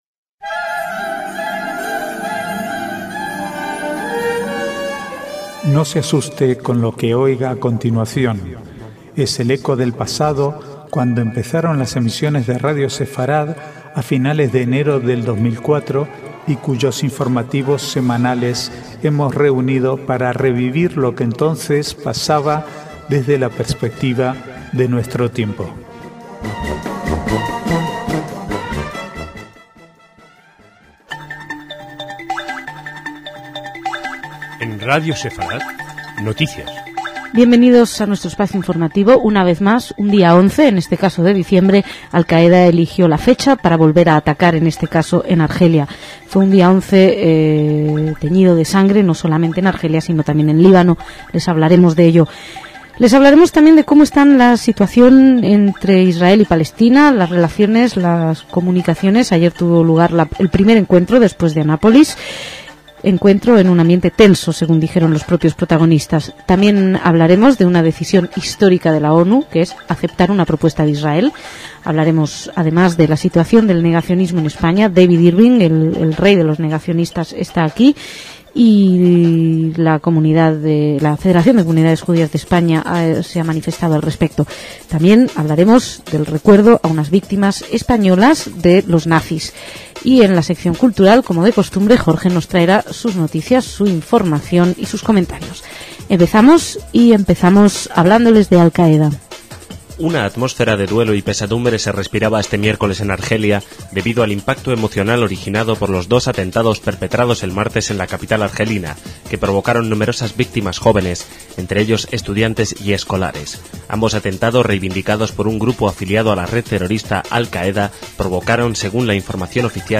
Archivo de noticias del 13 al 18/12/2007